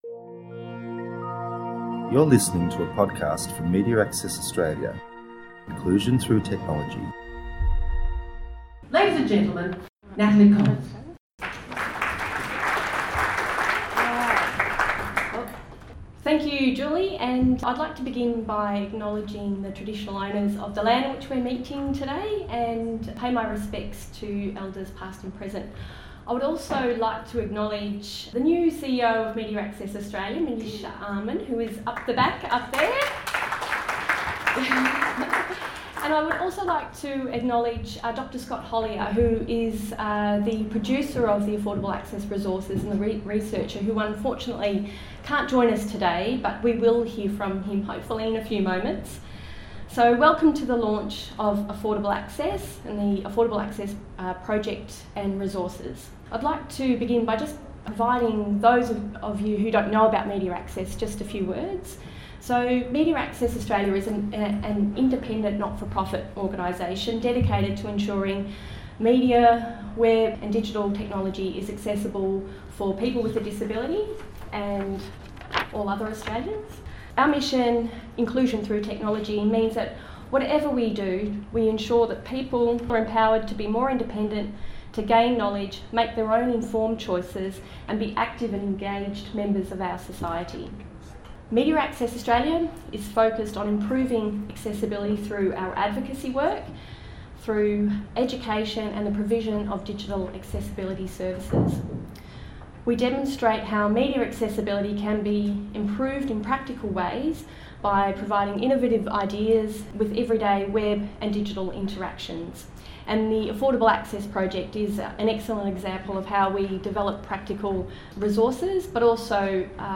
New Affordable Access website – listen to the informative launch presentation – Media Access Australia